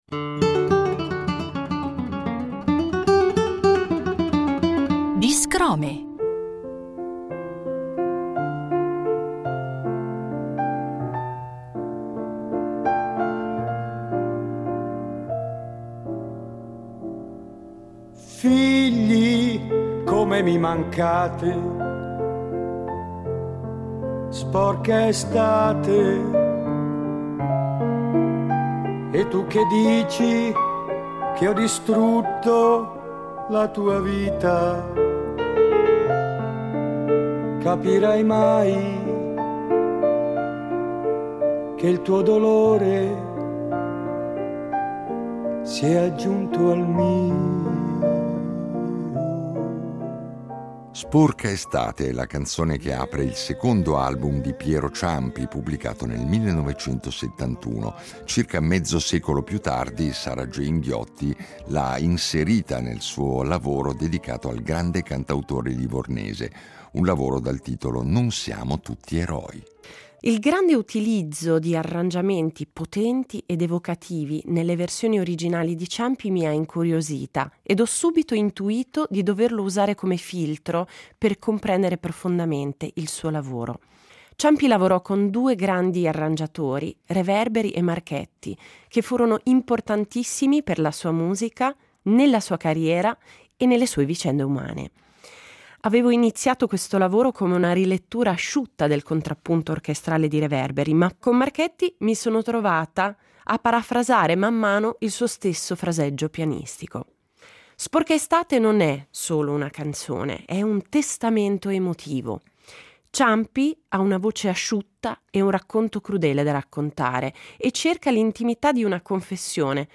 A ciascun episodio è dedicato un brano, punto di partenza per riflessioni musicali, linguistiche e persino antropologiche. L’apertura propone un frammento originale delle storiche incisioni di Piero Ciampi, come gesto di memoria e invocazione d’archivio.
In chiusura, l’ascolto integrale della rilettura musicale tratta dal disco Non siamo tutti eroi, interpretata da un ensemble interamente femminile.